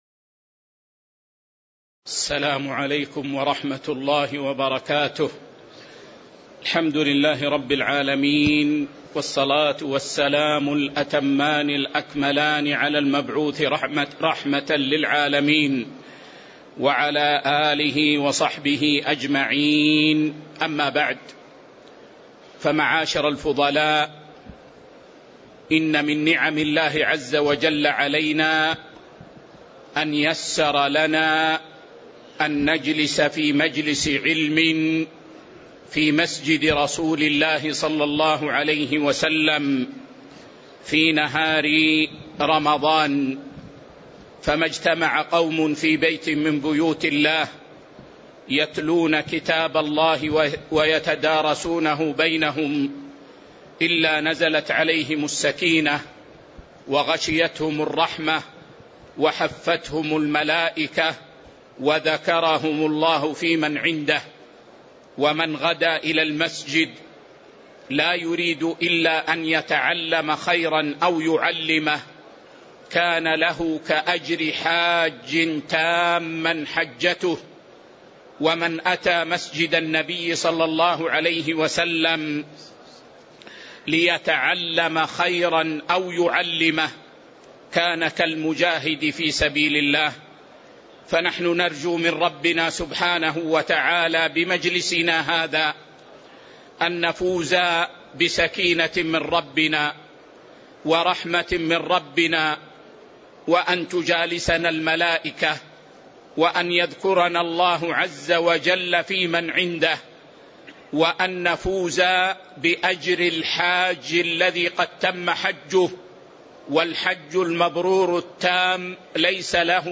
شرح دليل الطالب لنيل المطالب الدرس 396 كتاب الصيام 18 قوله ويسن صوم التطوع وأفضله يوم ويوم